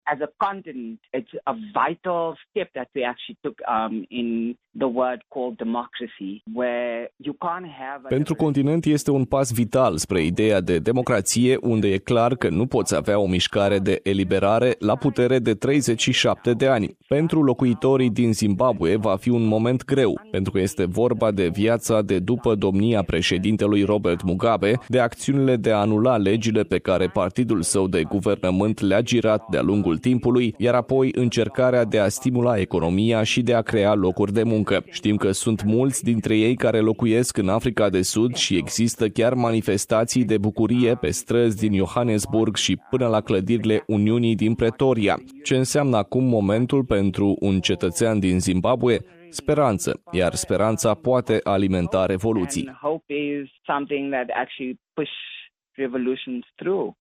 Comentariu tradus: